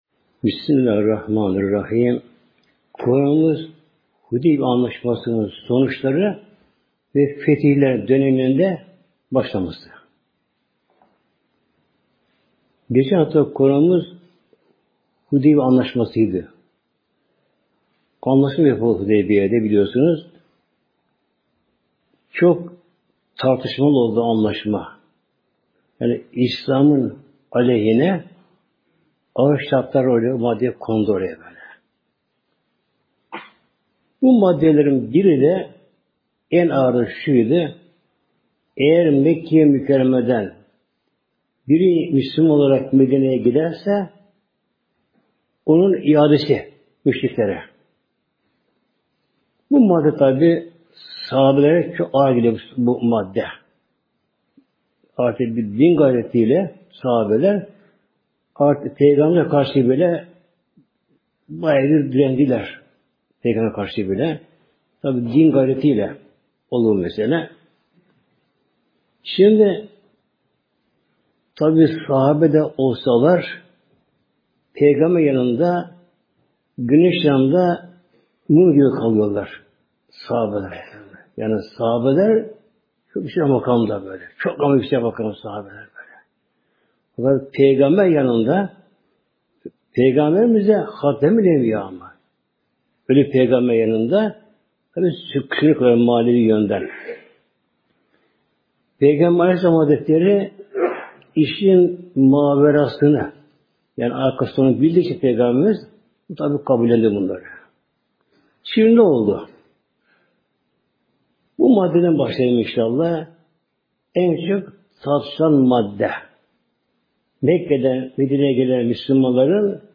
Sesli sohbeti indirmek için tıklayın (veya Sağ tıklayıp bağlantıyı farklı kaydet seçiniz)